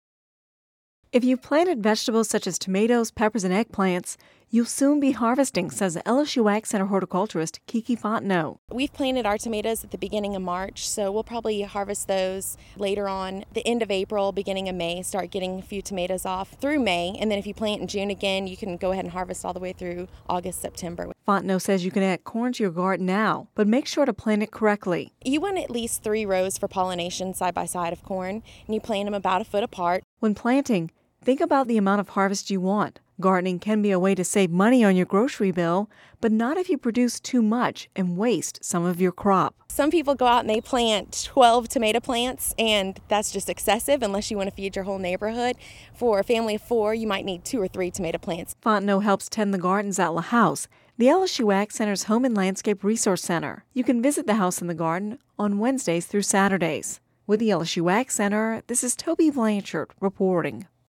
(Radio News 05/03/10) If you planted vegetables such as tomatoes